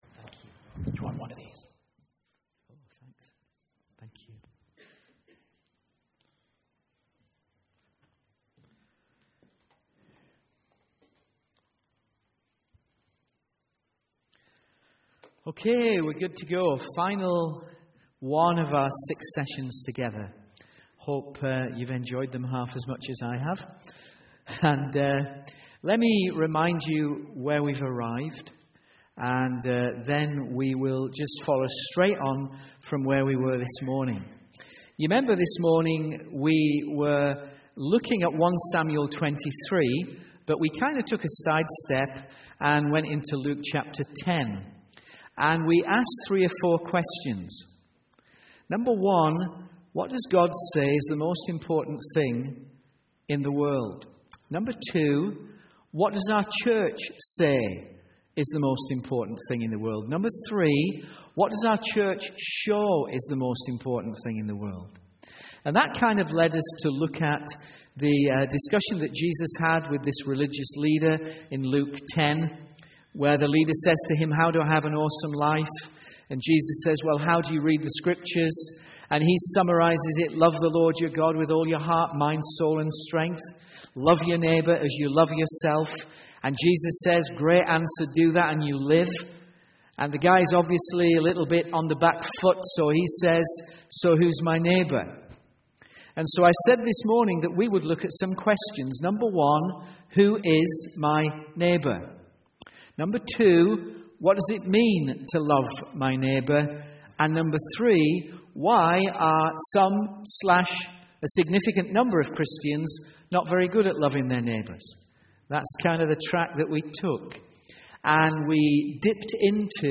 From Series: "Sunday Evening"